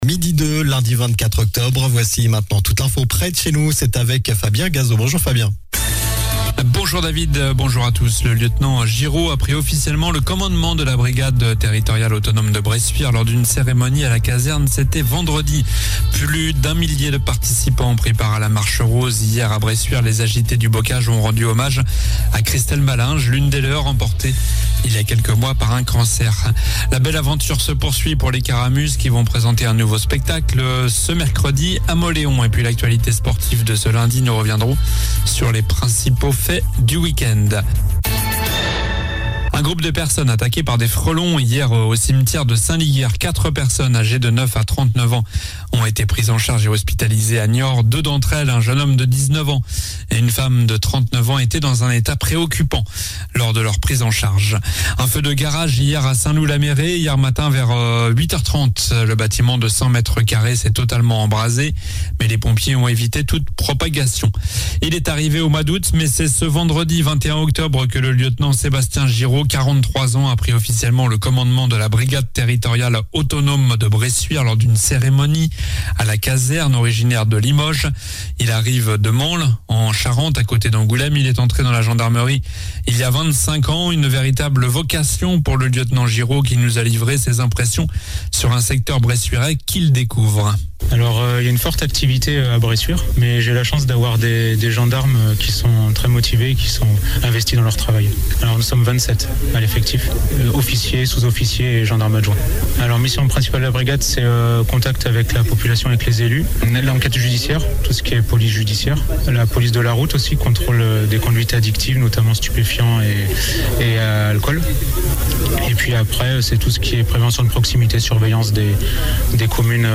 Journal du lundi 24 octobre (midi)